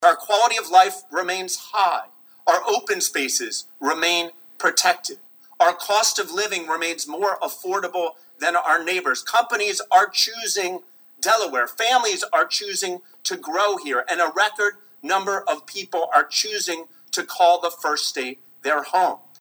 Governor Matt Meyer delivered his 2026 State of the State Address this afternoon.